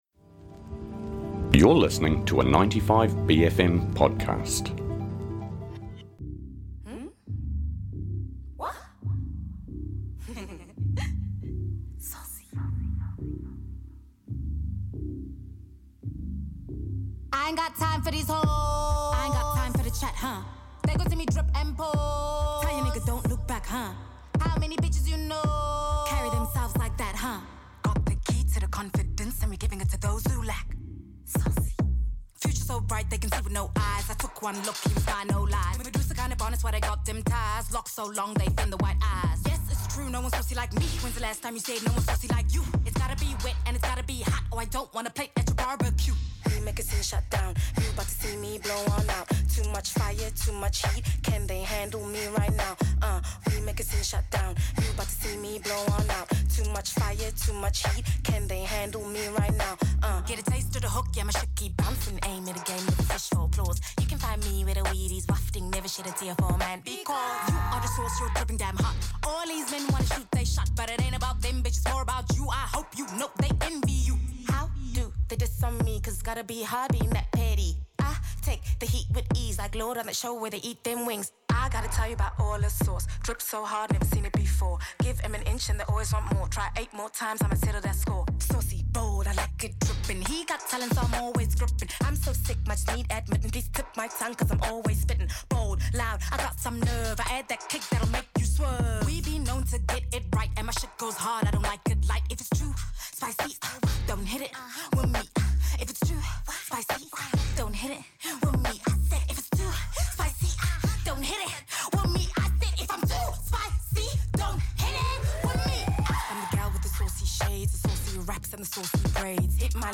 in the studio chatting about her new single Airplane Mode, and its accompanying music video.